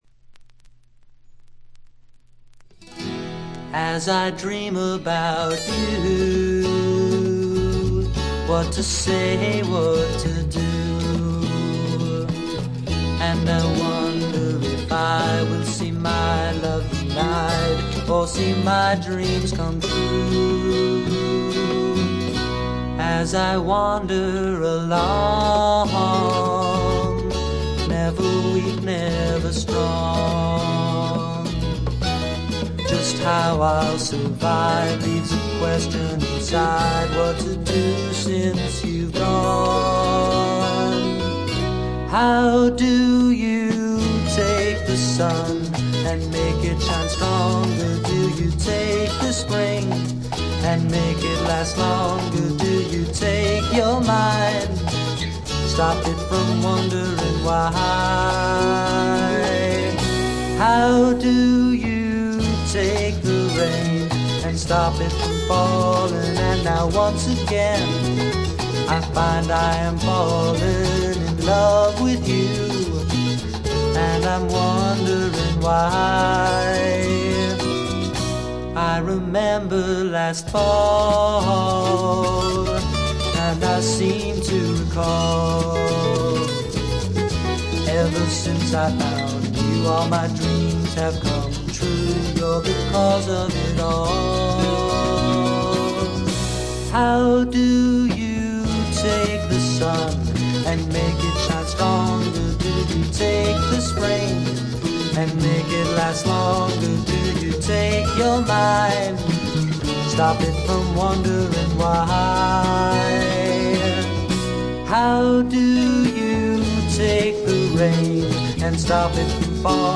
テキサスのシンガー・ソングライターが残した自主制作快作です。。
きらきらときらめくアコギの音、多くのマニアをノックアウトしたメローでクールな楽曲、時おりふっと見せるダウナーな感覚。